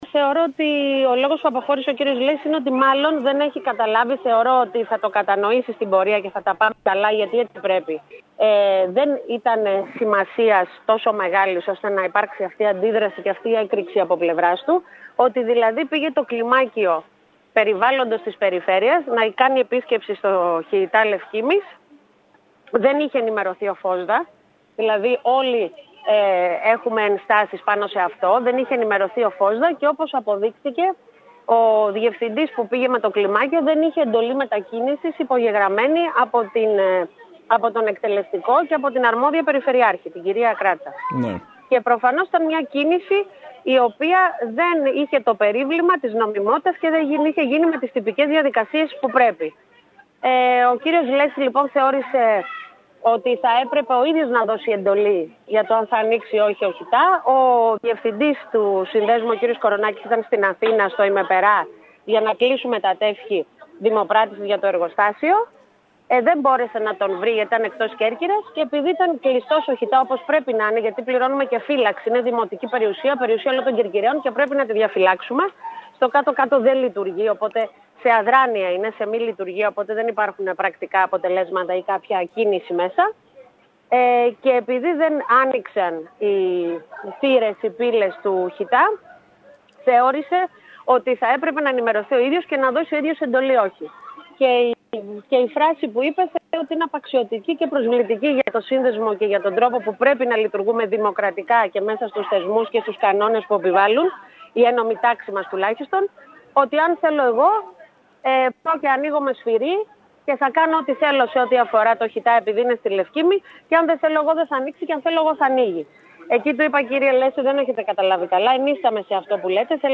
Από την πλευρά της, η Δήμαρχος Κεντρικής Κέρκυρας Μερόπη Υδραίου, τόνισε ότι το χώρος του ΧΥΤΑ Νότου όπως και ο αντίστοιχος του Τεμπλονίου αποτελούν ιδιοκτησία του ΦΟΣΔΑ, στη διοίκηση του οποίου μετέχουν αναλογικά όλοι οι Δήμοι του νομού.